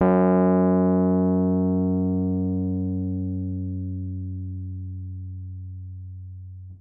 Rhodes_MK1